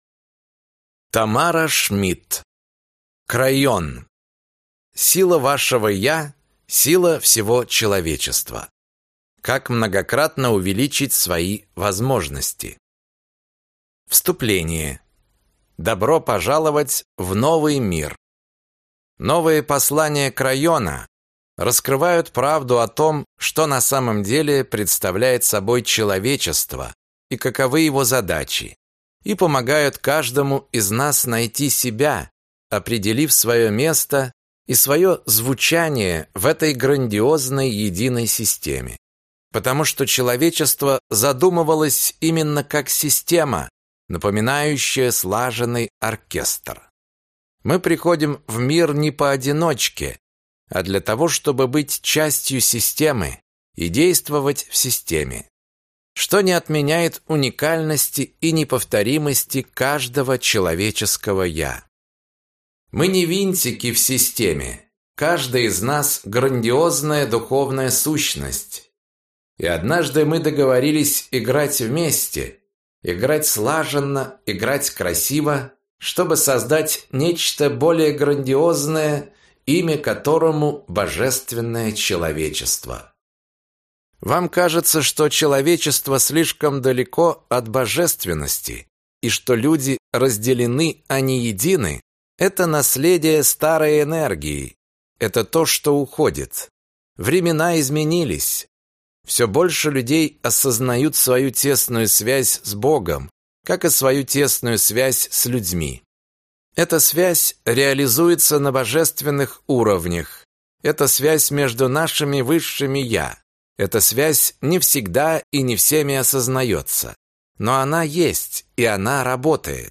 Аудиокнига Крайон. Сила вашего Я – сила всего человечества. Как многократно увеличить свои возможности | Библиотека аудиокниг